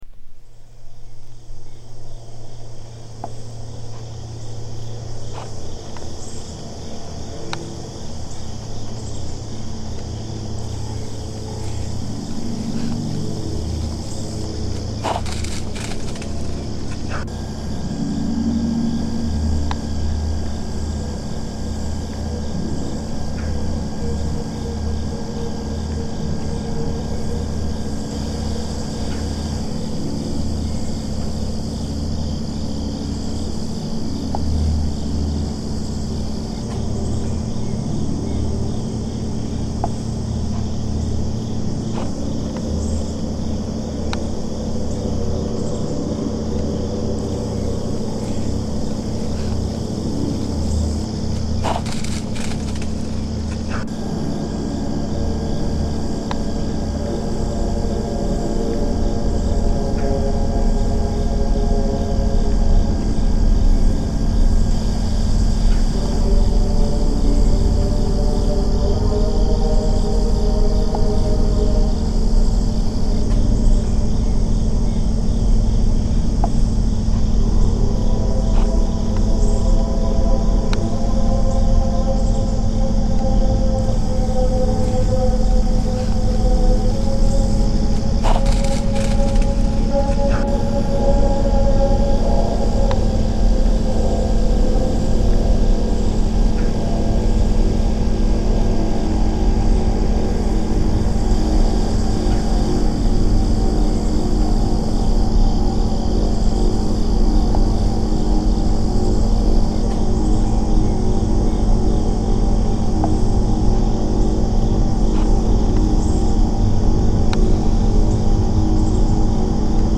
Ah, very atmospheric indeed!
*quake* Evilly spooky, or spookily evil...either way works!
Anyway...nice textures, as always.